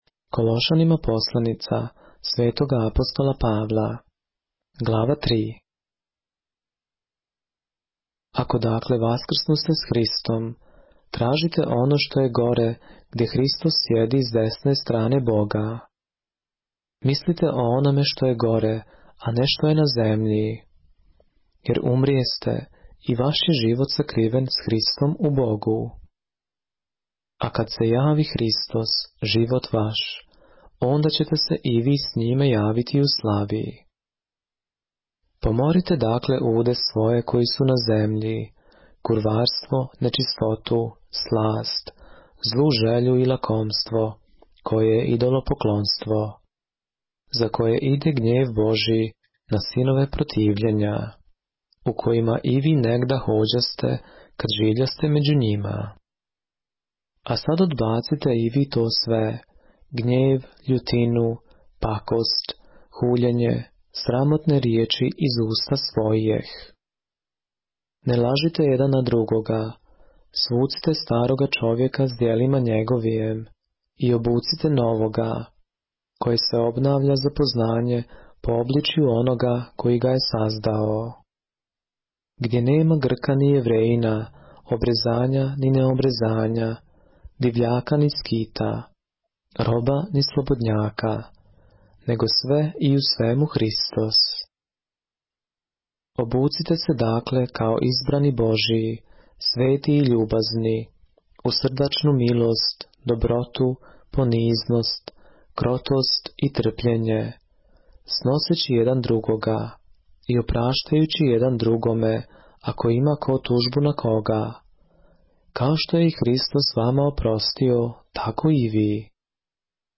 поглавље српске Библије - са аудио нарације - Colossians, chapter 3 of the Holy Bible in the Serbian language